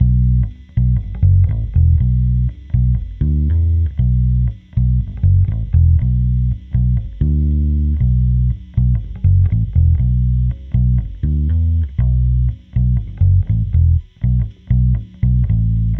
宇宙低音吉他第一部分
描述：k (单声道录音...)
Tag: 120 bpm Rock Loops Bass Guitar Loops 2.69 MB wav Key : C